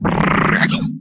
CosmicRageSounds / ogg / general / combat / creatures / alien / he / taunt1.ogg
taunt1.ogg